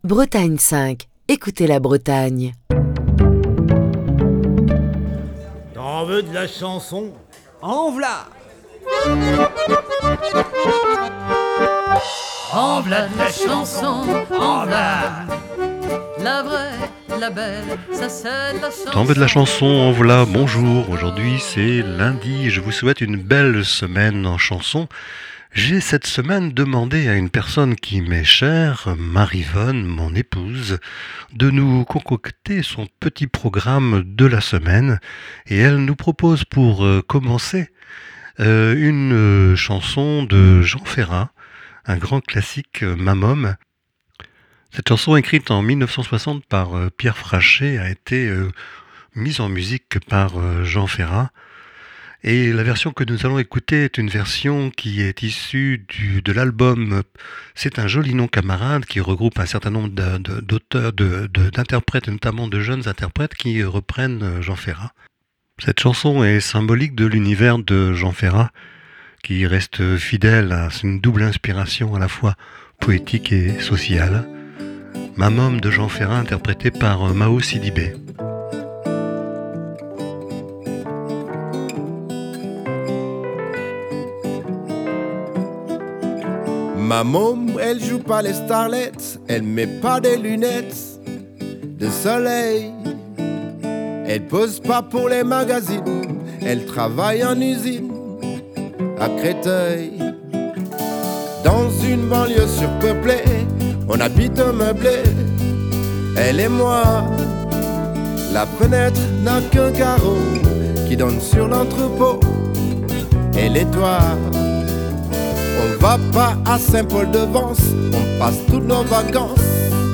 une reprise